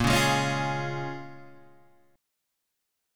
Bbm7 chord